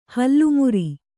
♪ hallu muri